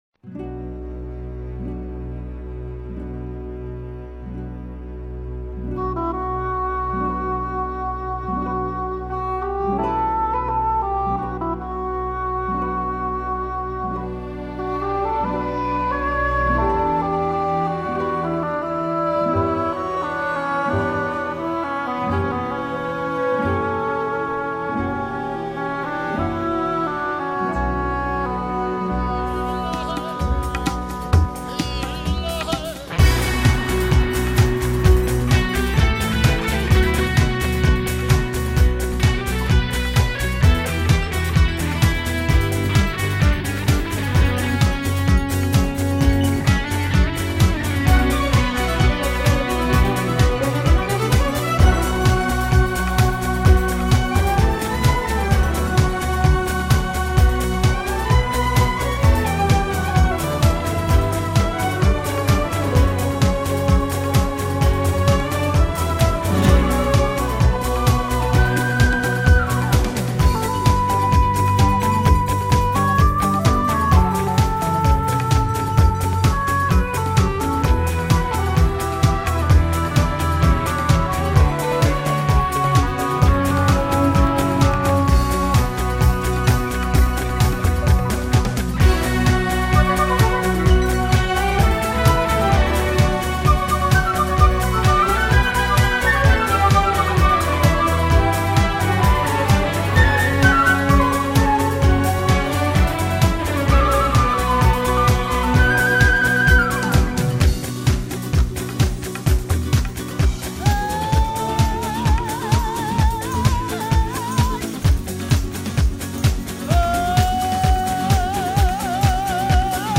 四名训练有素的年轻女性这次将与四名训练有素的年轻男性搭档。